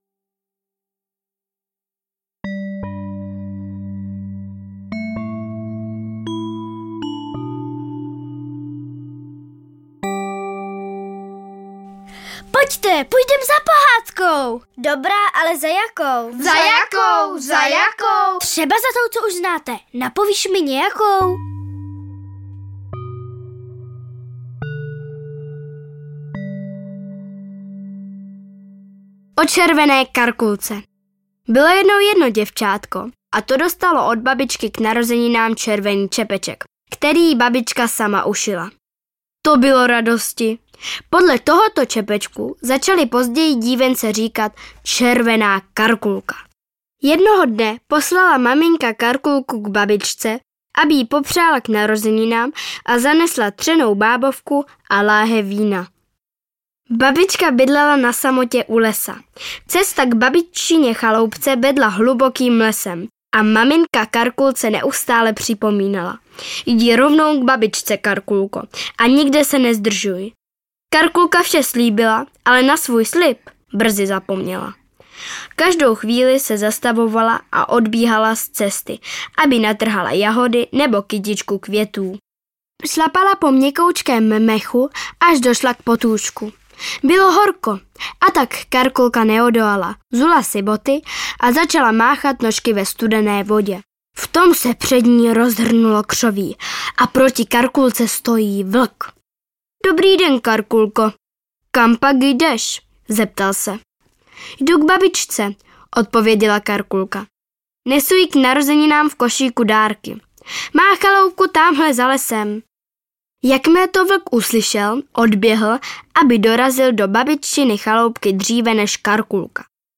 Nejznámější pohádky v podání dětských vypravěčů
Audiokniha